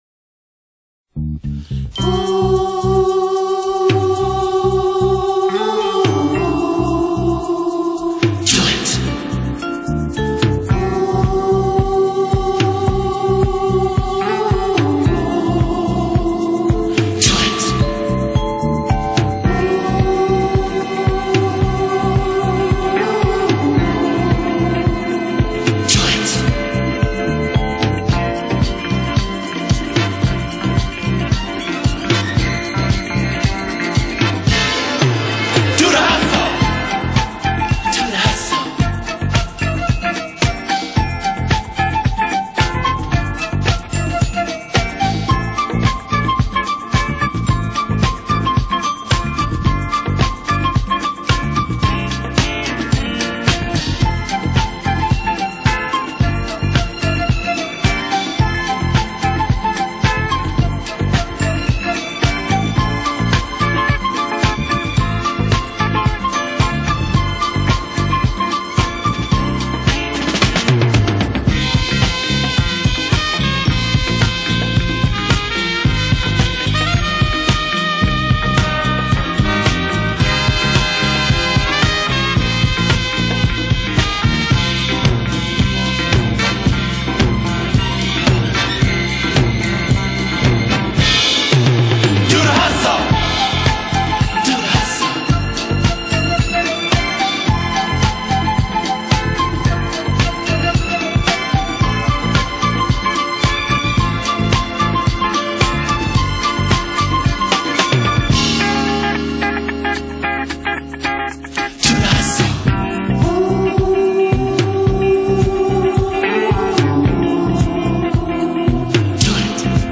是一首純音樂狄斯可
卻是狄斯可音樂史的經典舞曲